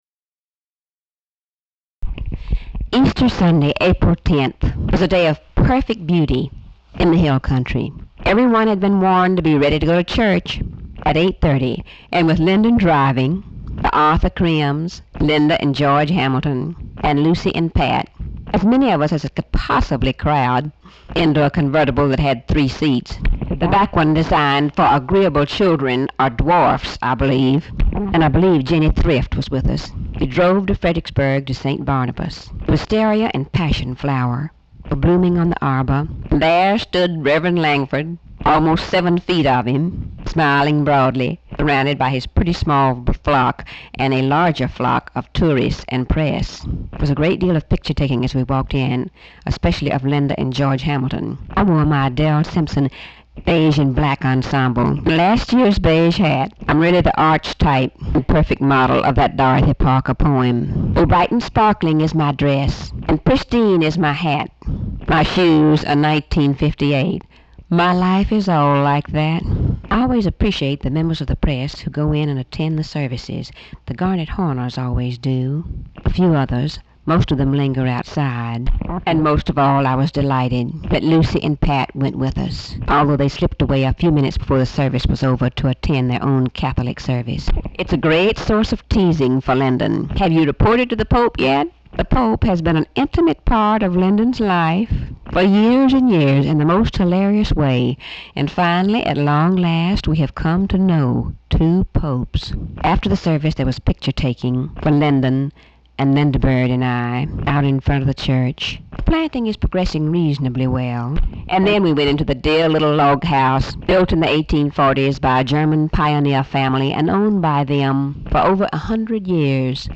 Audio diary and annotated transcript, Lady Bird Johnson, 4/10/1966 (Sunday) | Discover LBJ